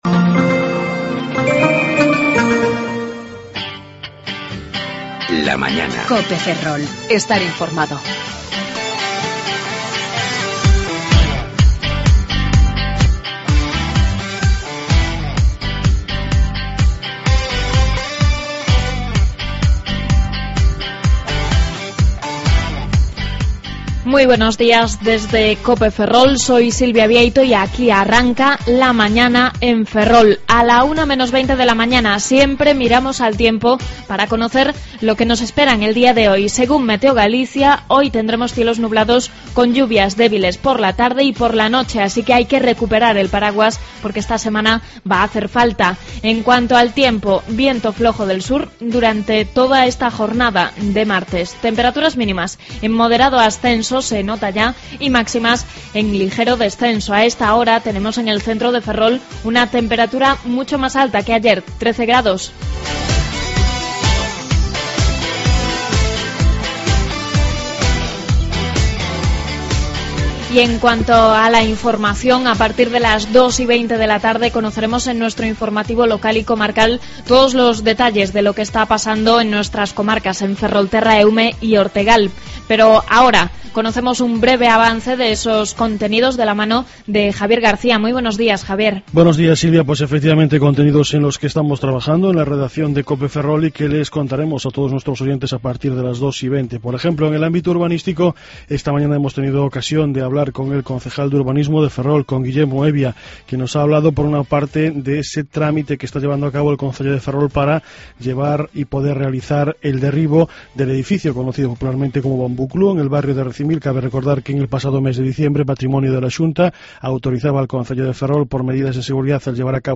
AUDIO: Avances informativos y contenidos de Ferrol, Eume y Ortegal.